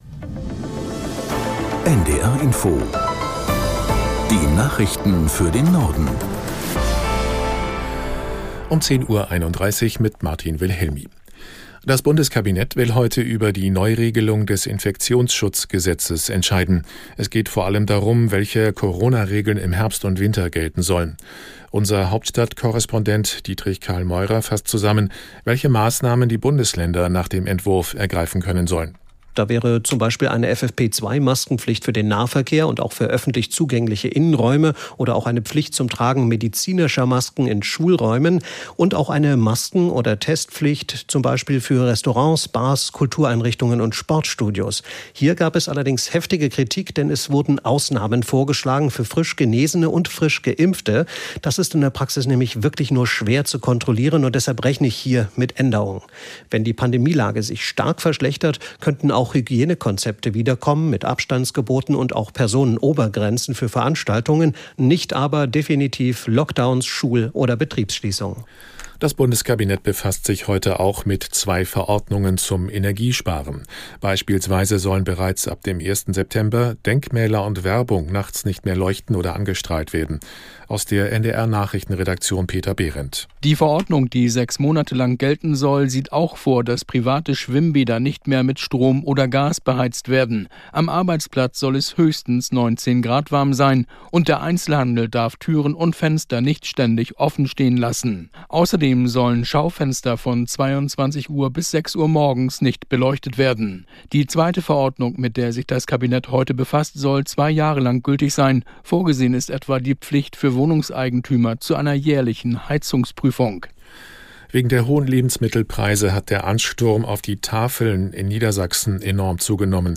Nachrichten - 24.08.2022